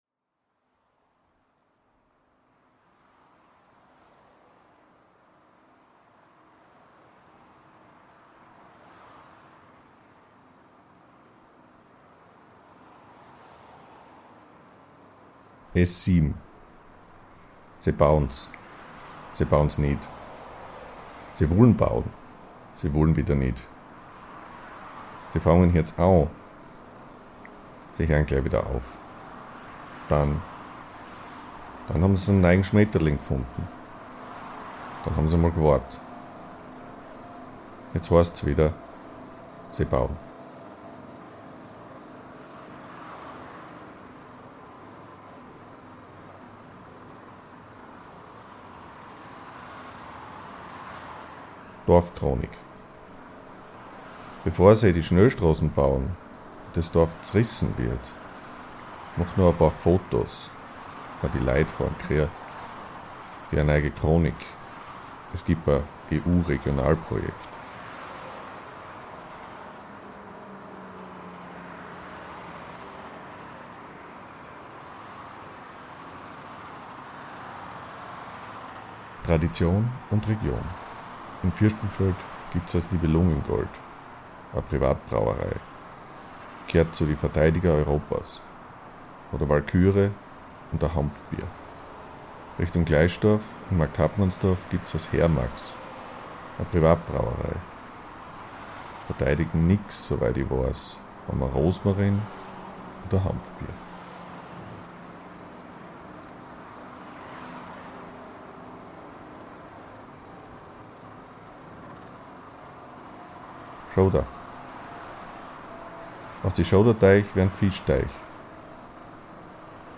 die in einer Variante des Oststeirischen verfasst sind (